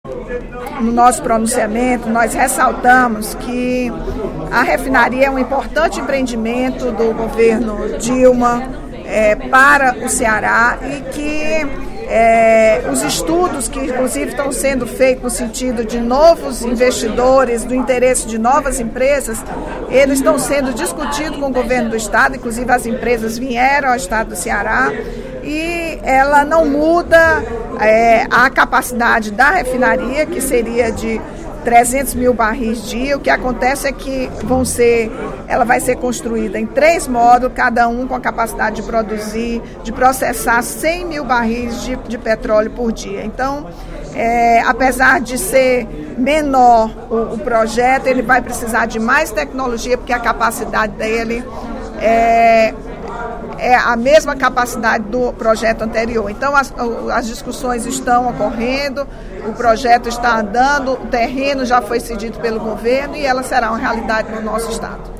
Durante o primeiro expediente da sessão plenária desta terça-feira (11/06), a deputada Rachel Marques (PT) também fez a defesa da parceria entre Petrobras e a GS Energy Corporation.